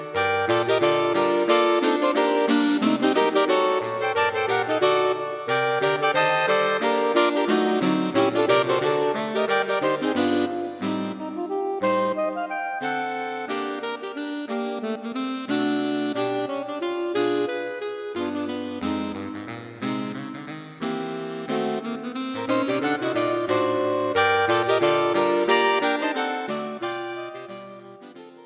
Saxophone Quartet for Concert performance